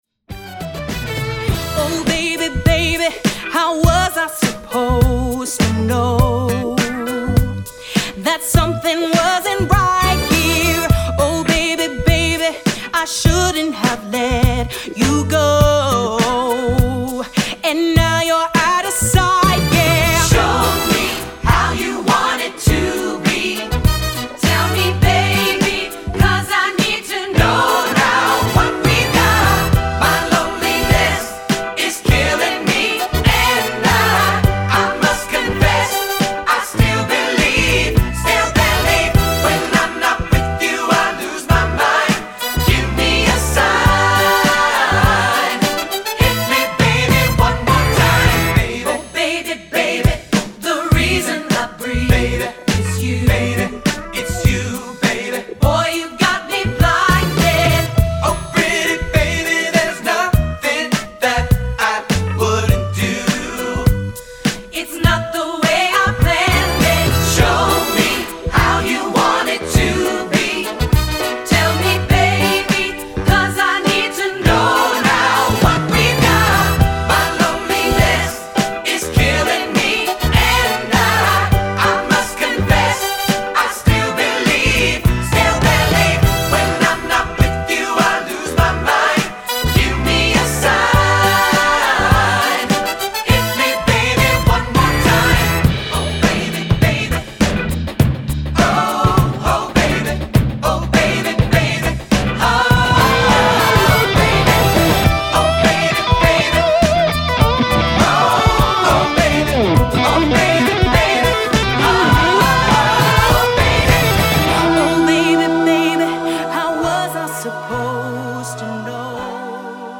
Choral Women's Chorus 70s-80s-90s Pop
SSA